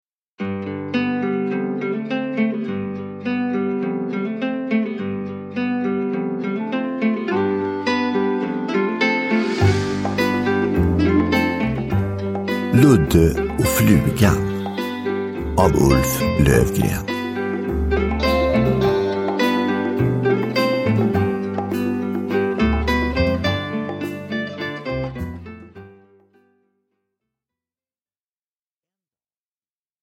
Ludde och flugan – Ljudbok – Laddas ner